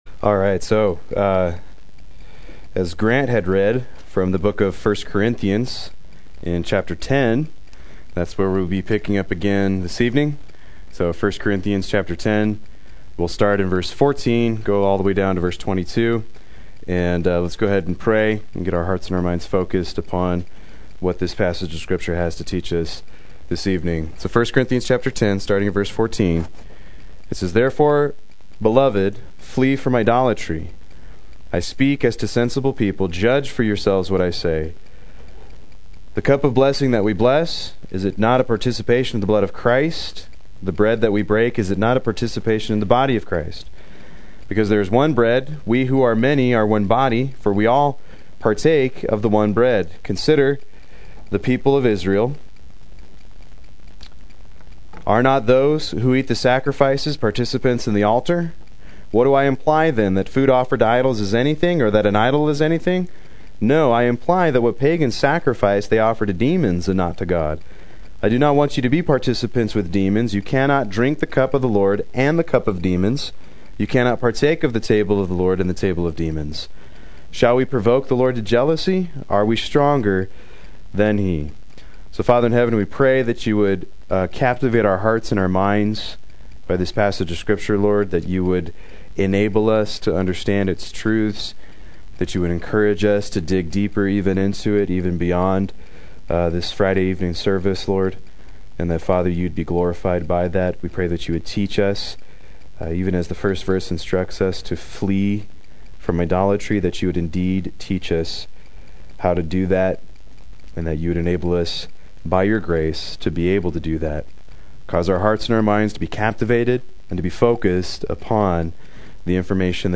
Proclaim Youth Ministry - 07/15/16
Play Sermon Get HCF Teaching Automatically.